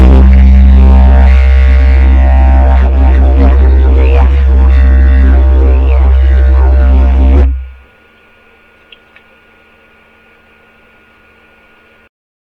MY DIDJERIDUs